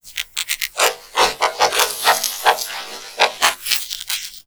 MONSTERS_CREATURES
ALIEN_Communication_29_mono.wav